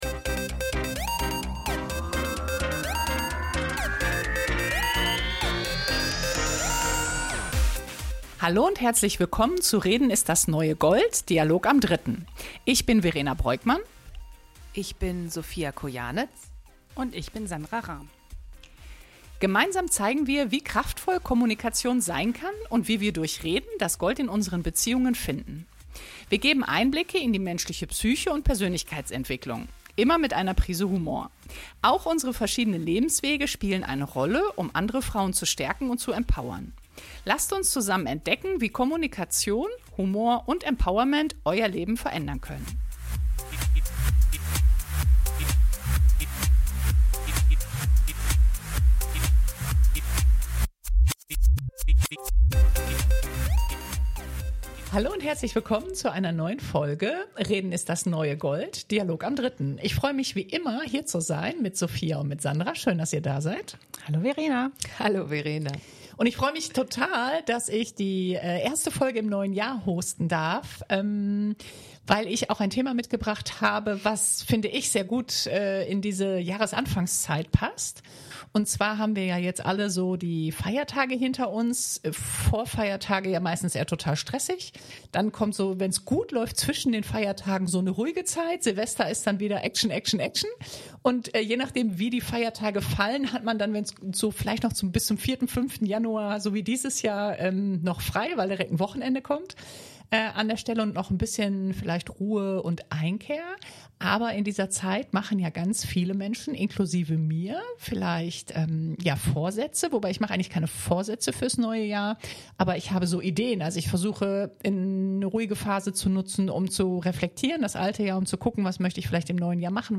Beschreibung vor 6 Tagen "Auf Kurs bleiben" ist ein ehrlicher Dialog über (Alltags-)Struggeln, innere Widerstände und diese Tage, an denen einfach nichts leichtfällt. Wir sprechen darüber, warum Durchhalten nicht immer laut, stark oder perfekt aussehen muss - und wie man trotzdem weitermacht, funktioniert und sich selbst nicht verliert.